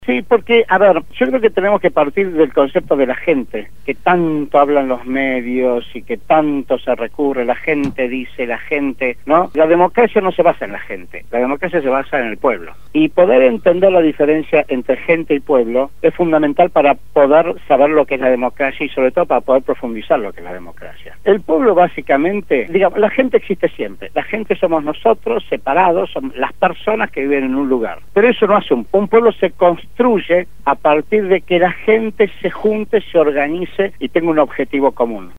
en el programa Punto de Partida de Radio Gráfica FM 89.3